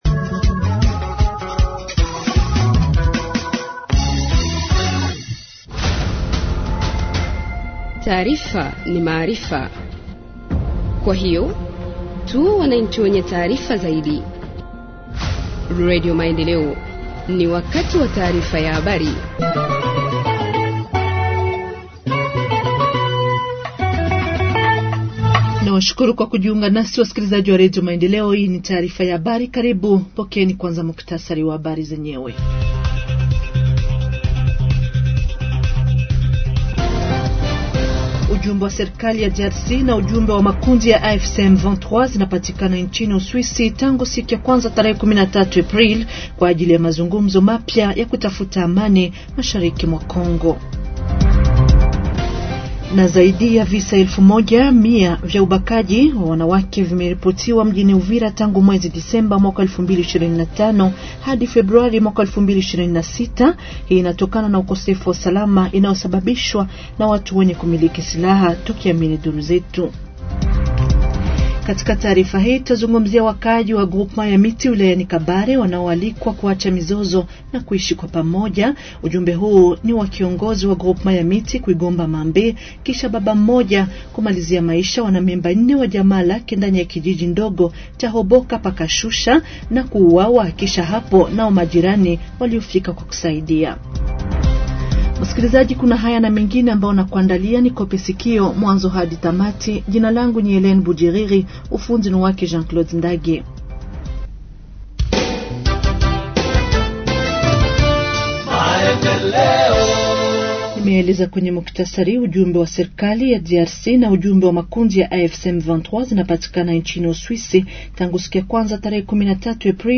Journal en Swahili du 14 Avril 2026 – Radio Maendeleo